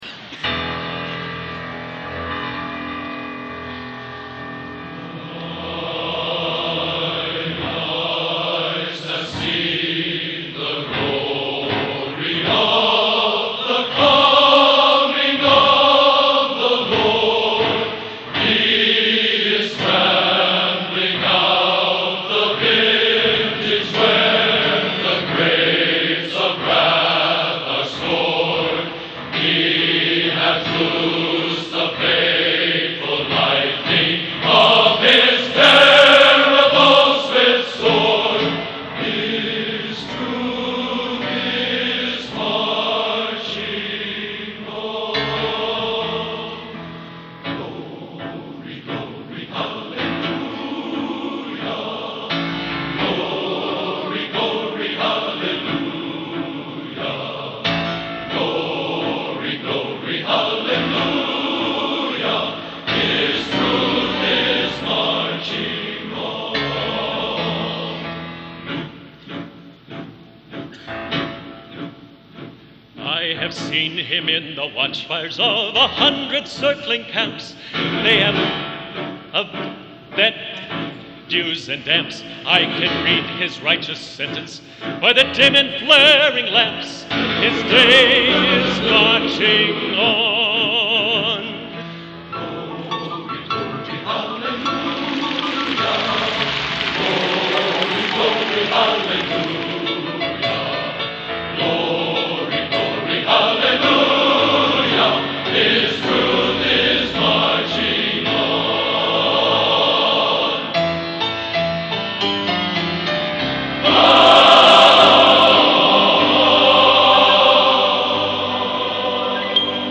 Genre: Patriotic | Type: